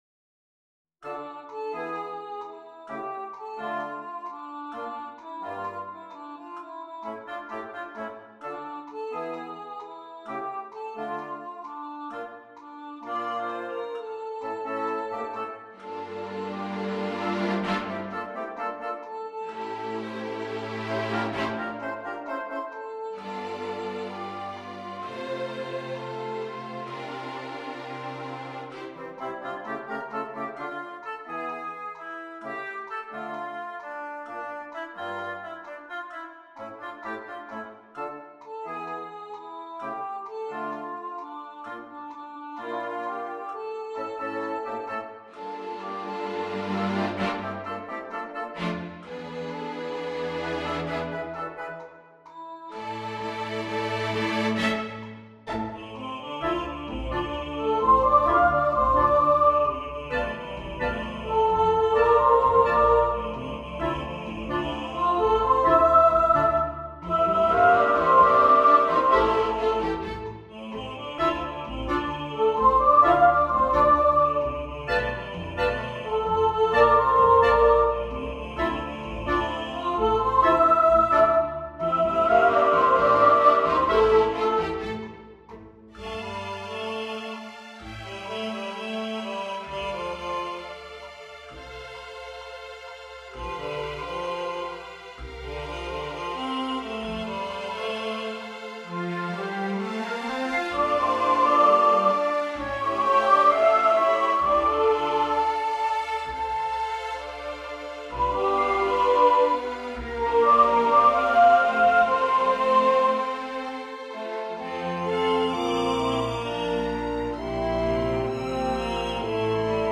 3-Orch-Die-Suche-nach-Verlorenem-2.mp3